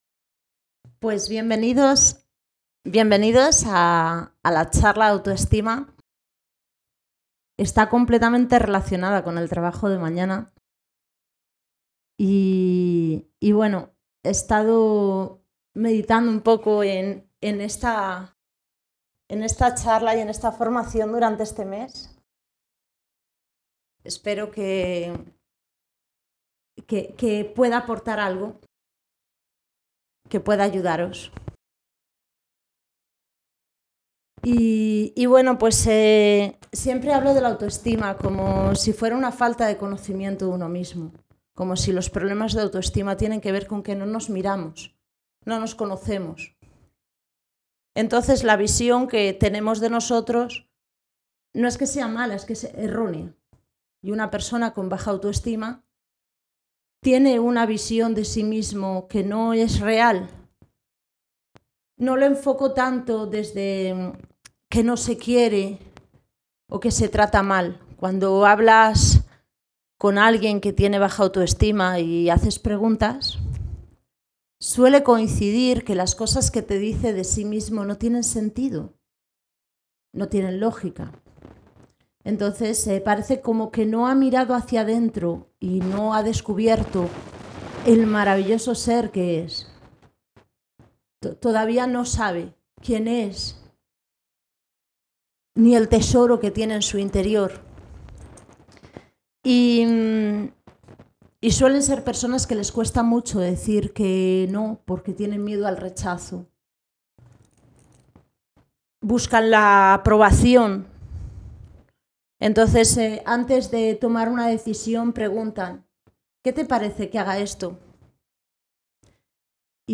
Charla sobre autoestima, 1ª parte